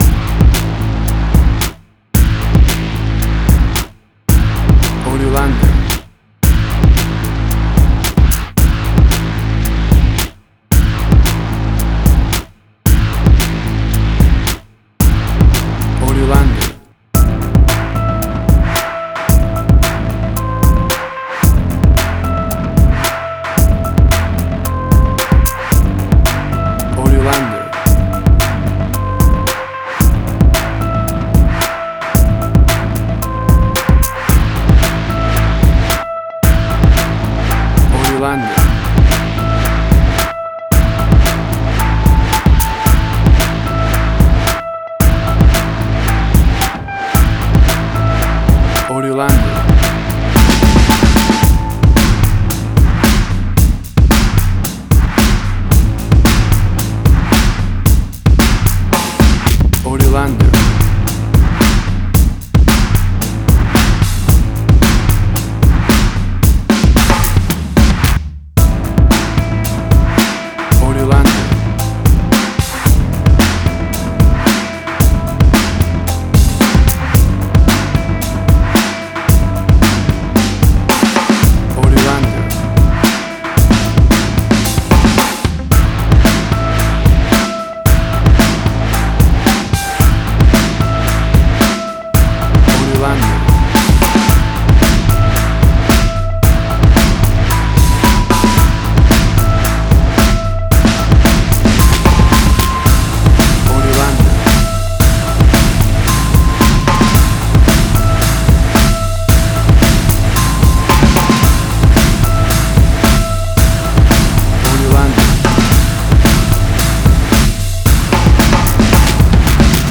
Cinematic Industrial Sci-fi
Tempo (BPM): 111